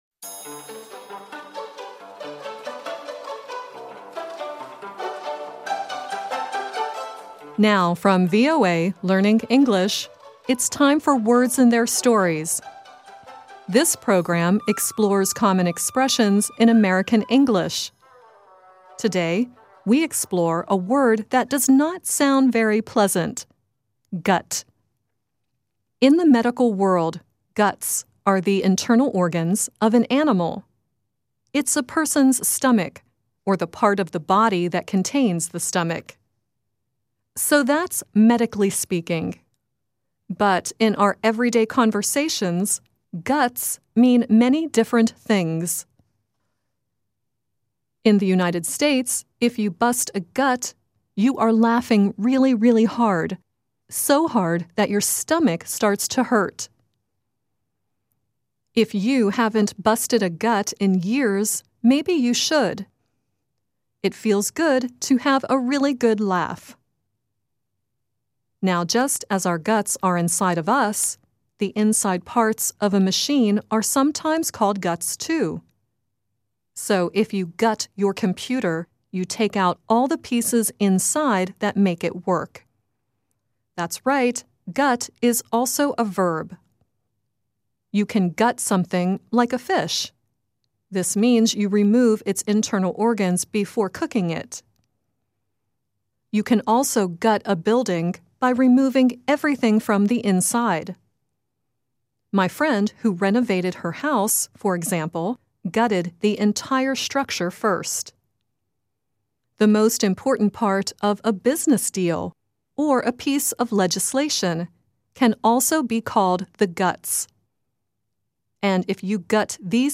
It is the theme song from the American television cartoon Adventure of the Galaxy Rangers.